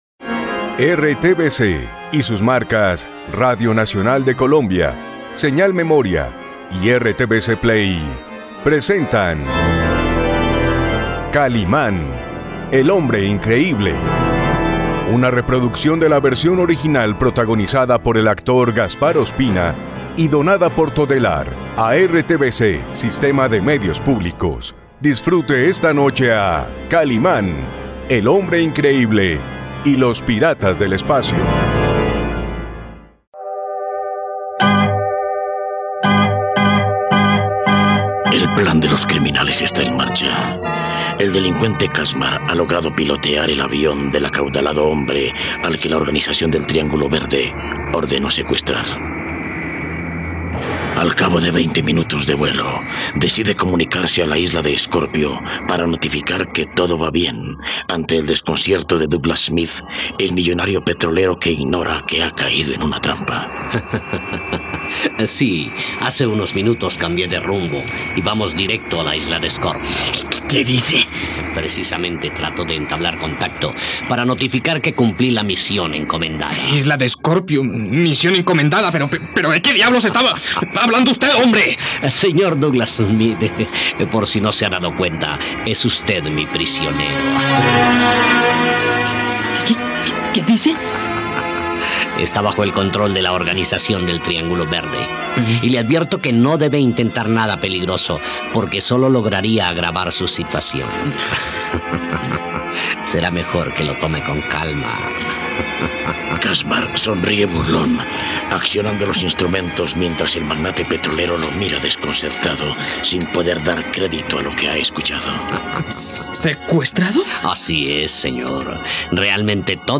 ..No te pierdas la radionovela completa de Kalimán y los piratas del espacio.